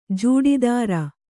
♪ jūḍidāra